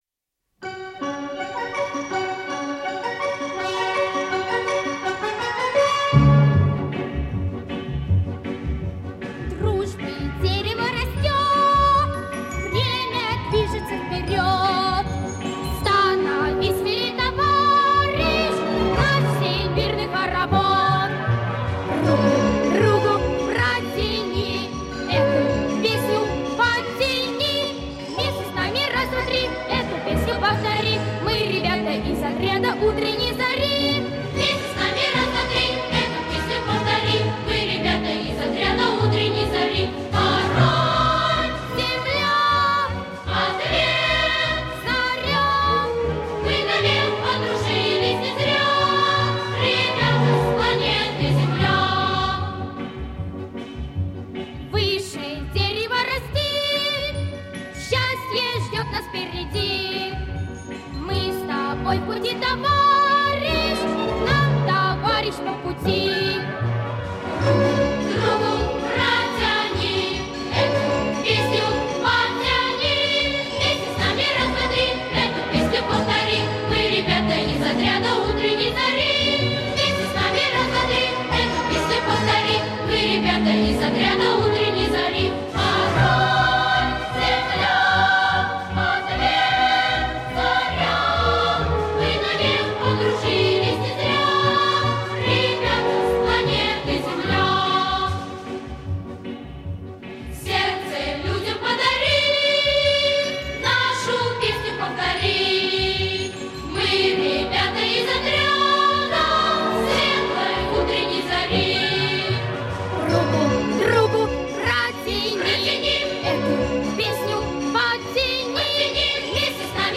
• Жанр: Детские песни